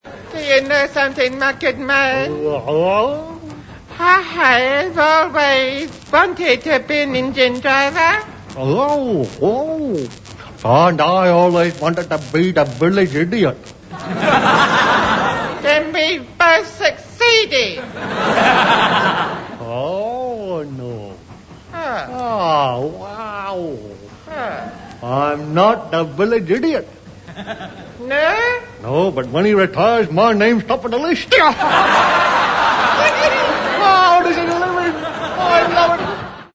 quote from an old BBC Goon Show with Bluebottle and Eccles driving an